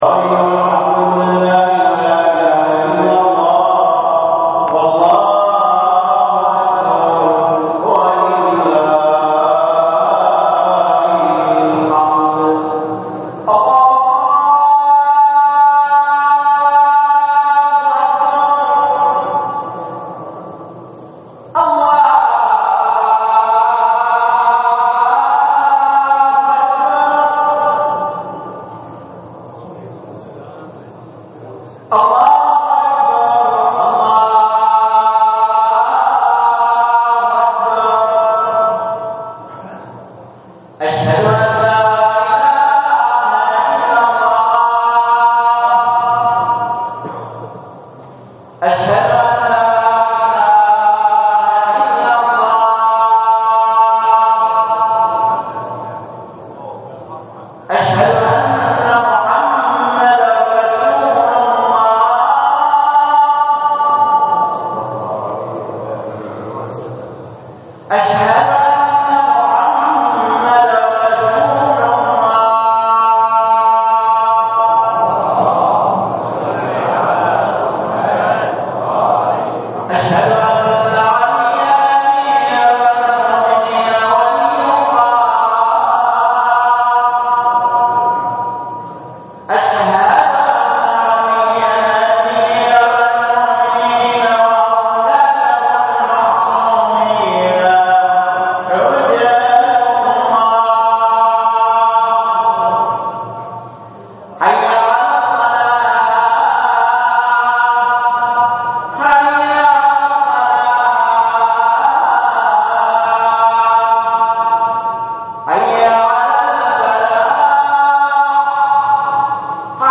للاستماع الى خطبة الجمعة الرجاء اضغط هنا